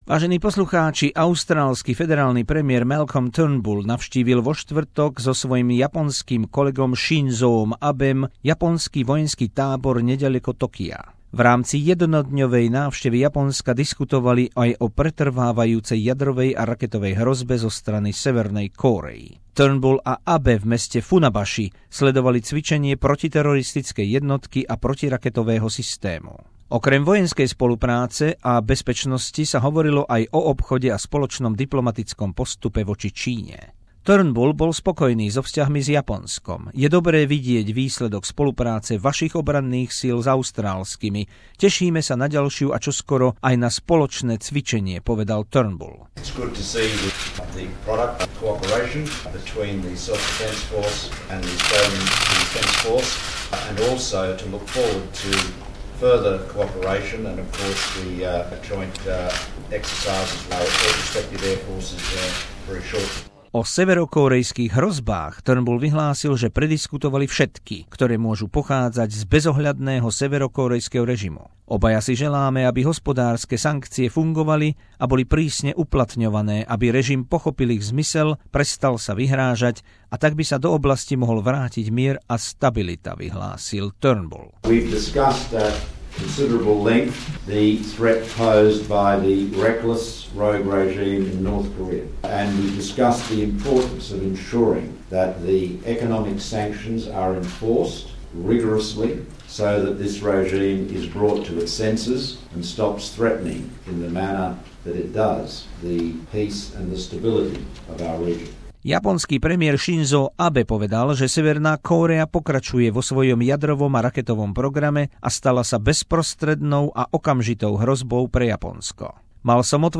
Reportáž z jednodňovej návštevy premiéra Malcolma Turnbulla v Japonsku.